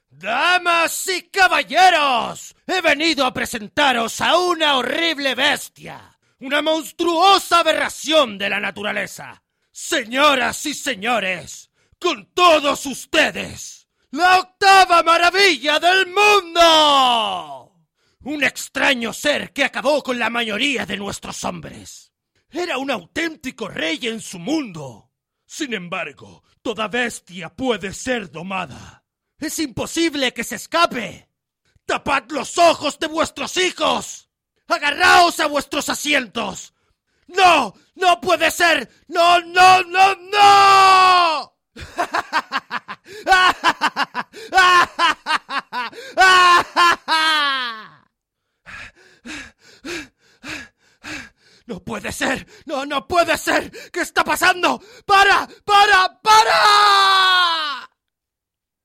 kanarisch
Sprechprobe: Sonstiges (Muttersprache):
INTENSE VOICEOVER.mp3